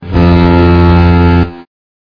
FOGHORN.mp3